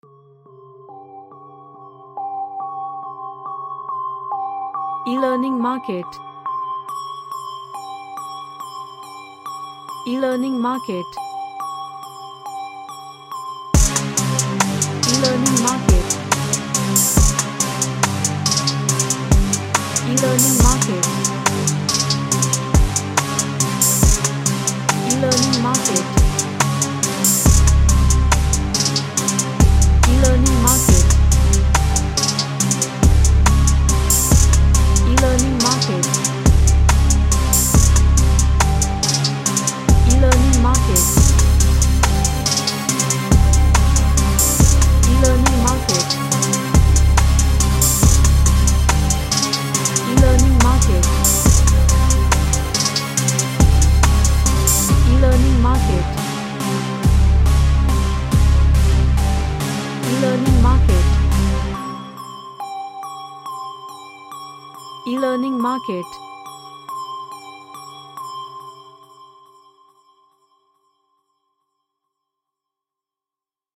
A hard and aggresive trap track
Dark / Somber